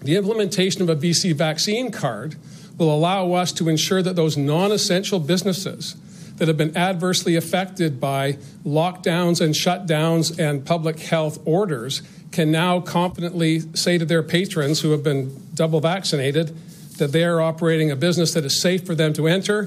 – John Horgan – Premier of British Columbia